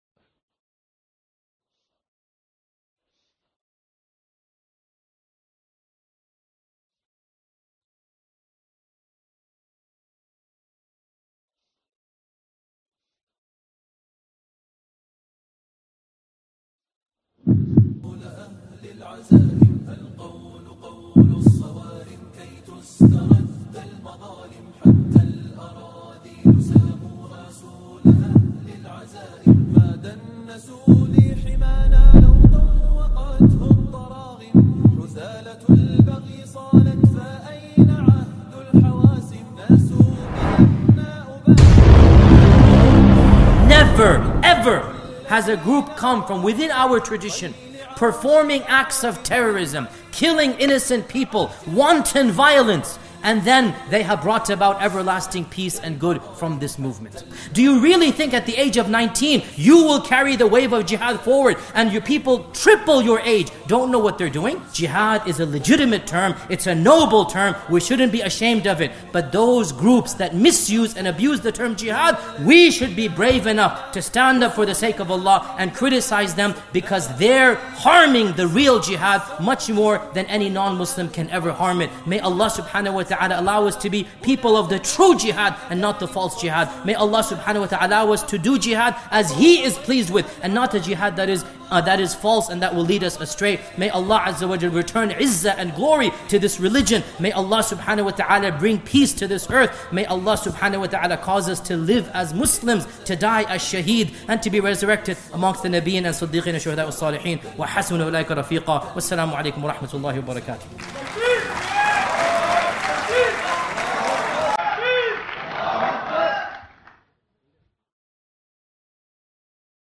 In this extensive and courageous lecture, a leading American Muslim scholar tackles the question head-on: are the modern self-proclaimed jihadist movements following the authentic teachings of Islam, or are they repeating the same catastrophic mistakes of the Khawarij, the earliest deviant sect in Islamic history?